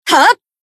BA_V_Marina_Battle_Shout_1.ogg